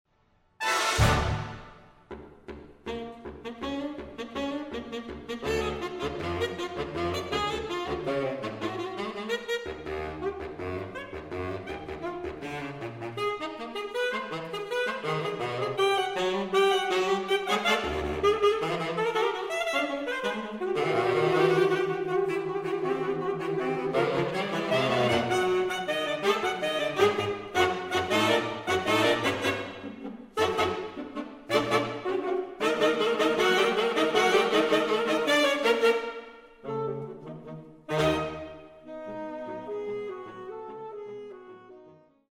The license plate and the musical form of this saxophone feature have the same name…it starts with an ‘F.’
fugue